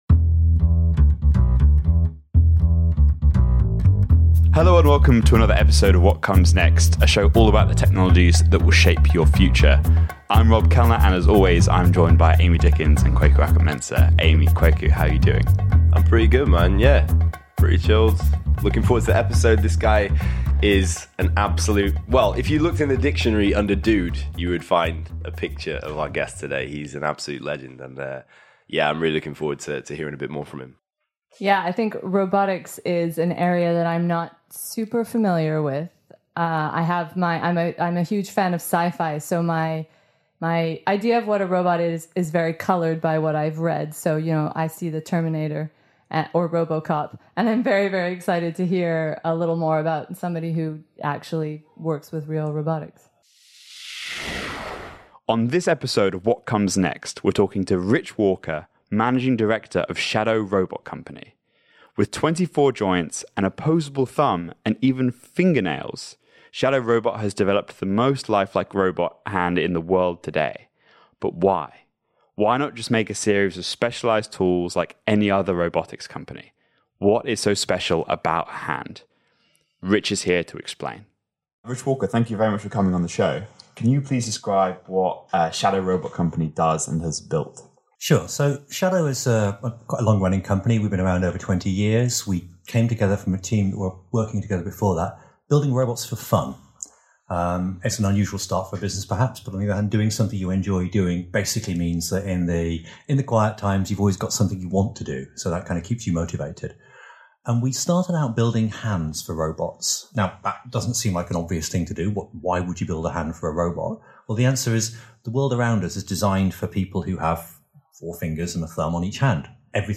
Tech Entrepreneur Business GrantTree Studios Innovation Science Future Technology Startups Next Interviews Content provided by GrantTree Studios.